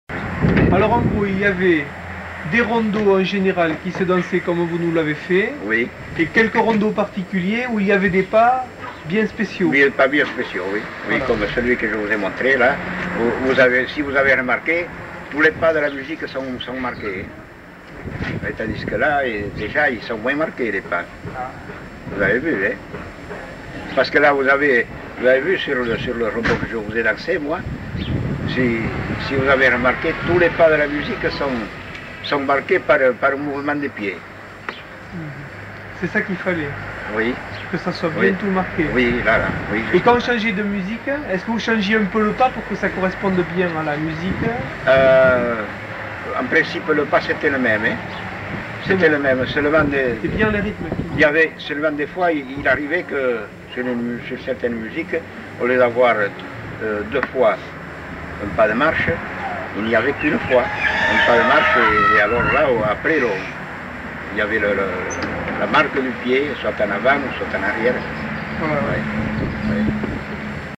Lieu : Mimizan
Genre : témoignage thématique